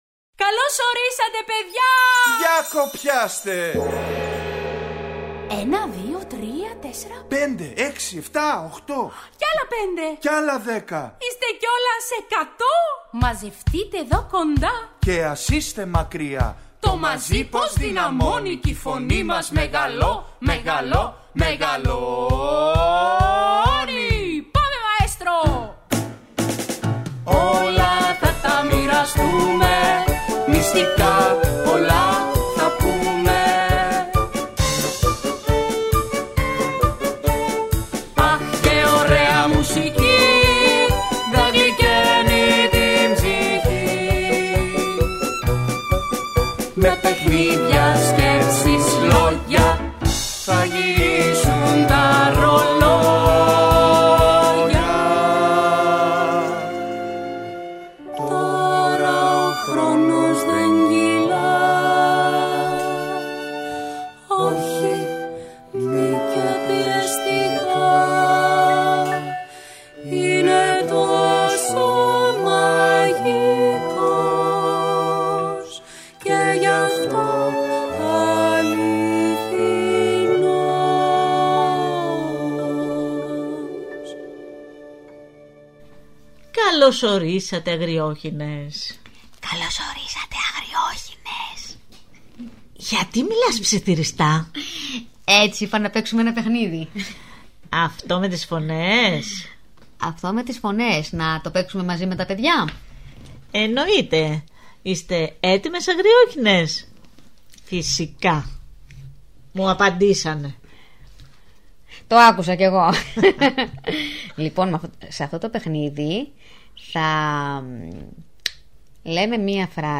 Ακούστε στην παιδική εκπομπή ‘’Οι Αγριόχηνες’’ το παραμύθι «Ο Βρασίδας ο Βολίδας» της Άννα Λιένας.